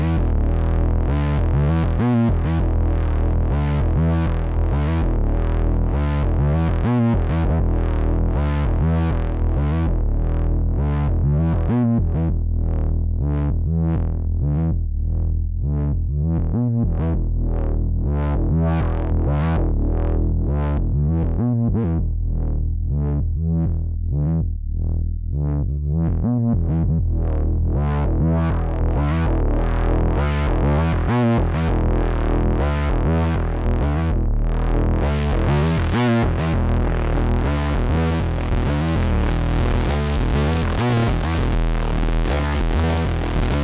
描述：BPM130非EQ非Reverb非Widener。使用Steinberg HalionSonic2 Voltage创建了这个声音。
标签： 电子 合成器 电子乐 房子 跳舞 低音
声道立体声